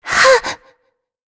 Shea-Vox_Attack1.wav